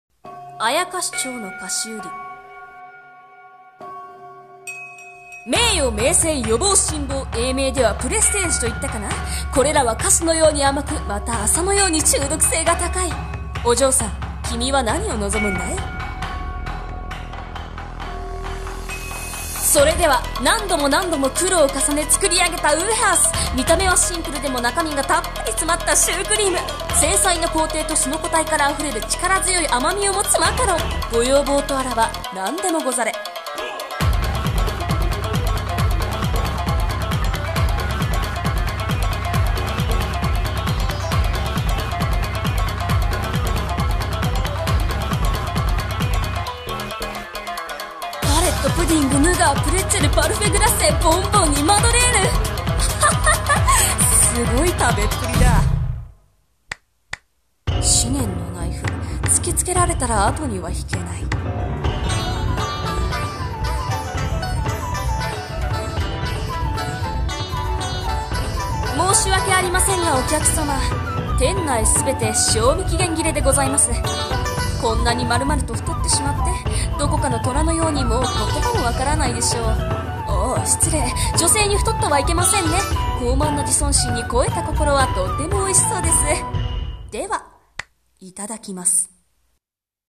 CM風声劇「妖町の菓子売